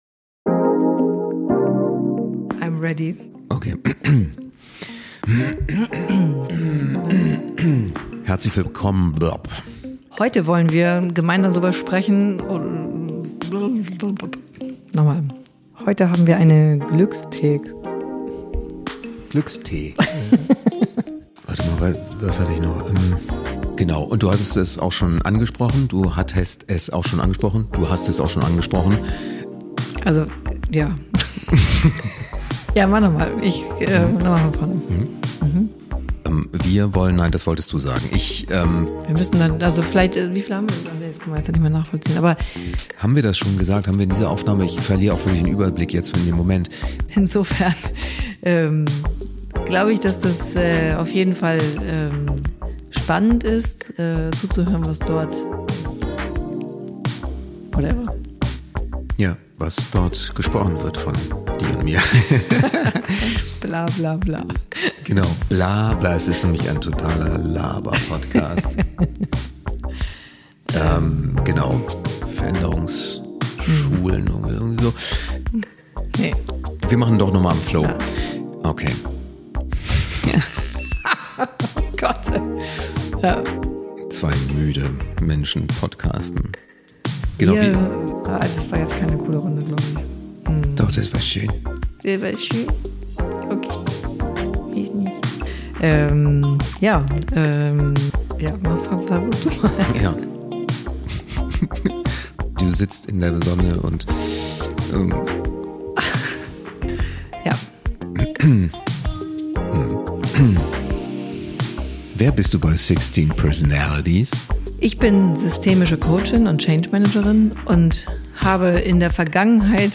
Unsere Outtakes… ein Zusammenschnitt von Pannen bei der Aufnahme…